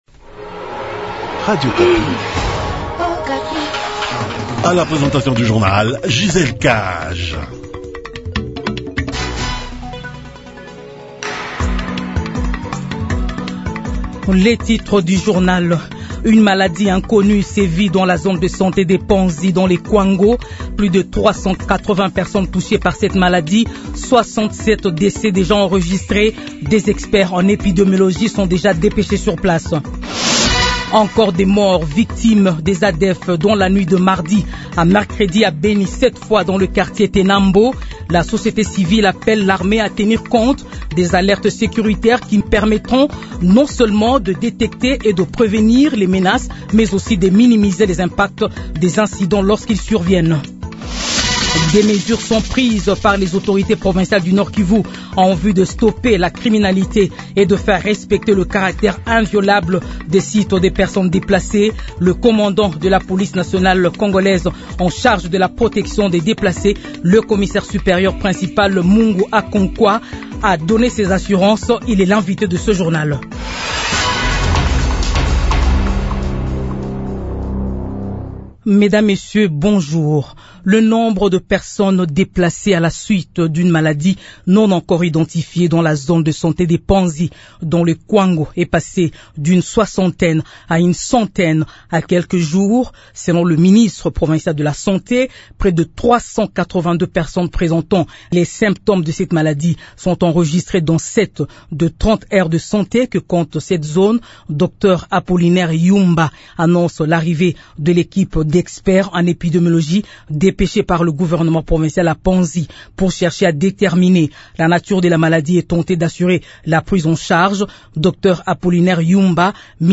Journal français de 12 heures